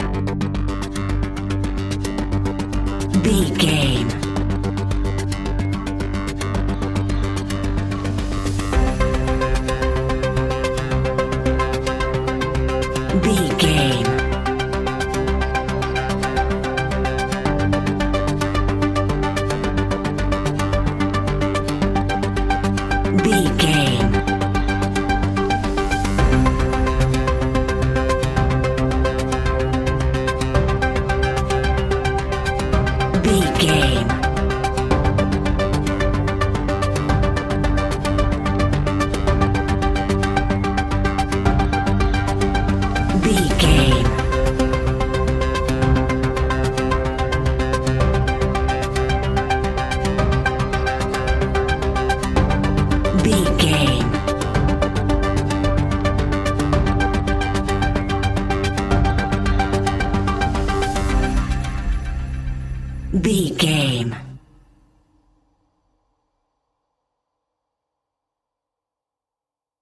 In-crescendo
Thriller
Aeolian/Minor
ominous
dark
eerie
instrumentals
horror music
Horror Pads
horror piano
Horror Synths